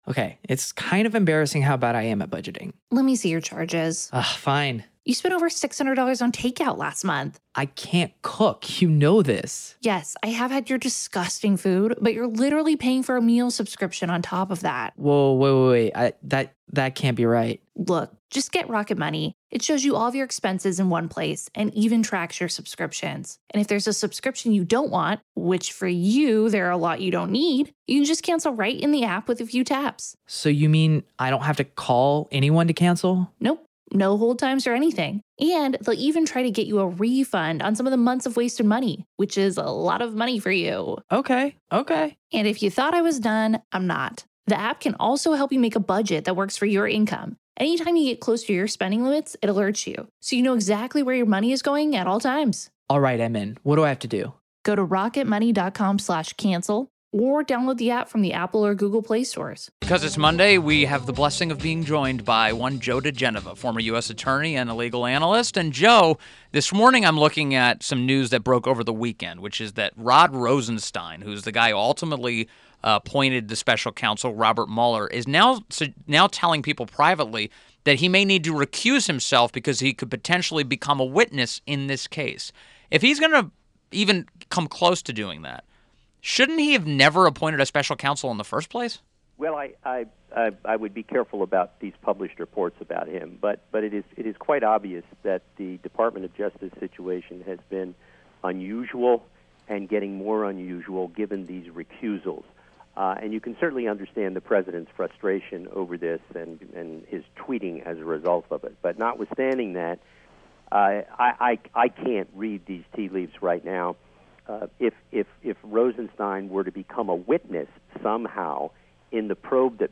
WMAL Interview - JOE DIGENOVA 06.19.17